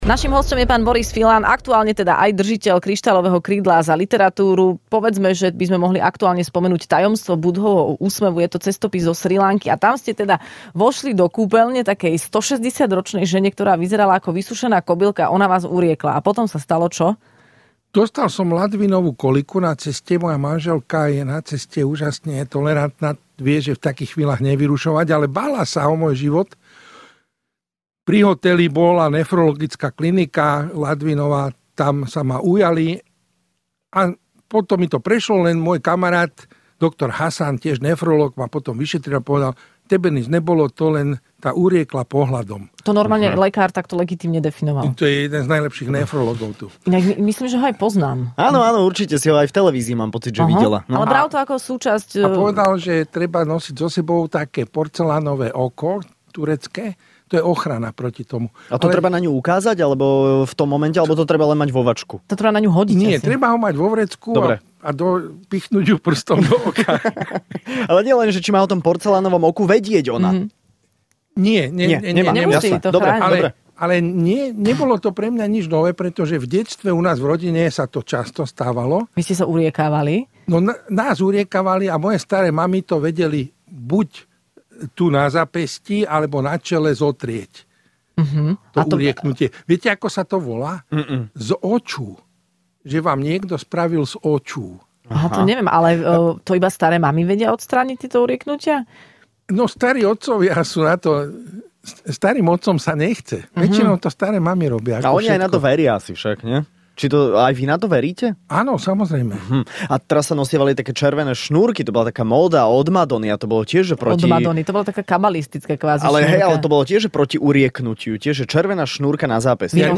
Hosťom v Rannej šou bol cestovateľ a spisovateľ Boris Filan, ktorý dostal aj ocenenie